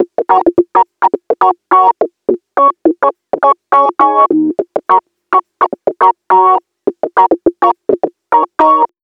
RI KEYS 3.wav